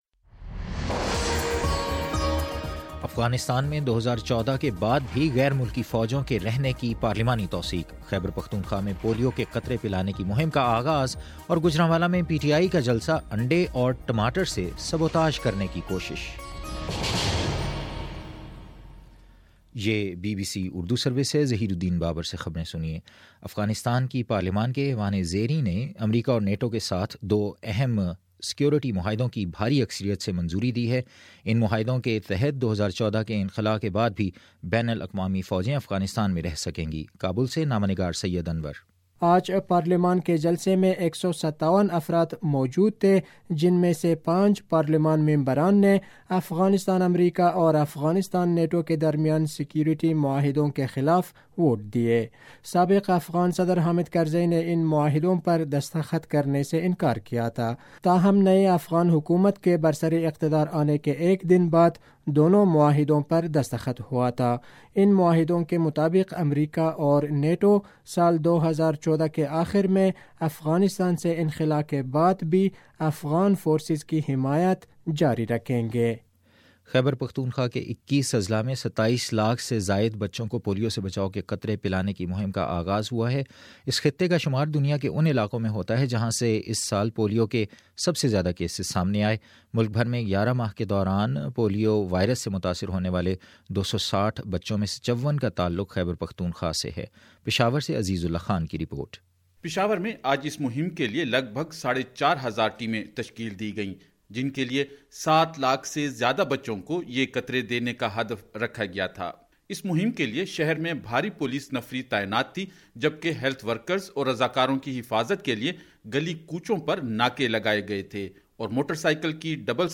نومبر23: شام سات بجے کا نیوز بُلیٹن
دس منٹ کا نیوز بُلیٹن روزانہ پاکستانی وقت کے مطابق صبح 9 بجے، شام 6 بجے اور پھر 7 بجے۔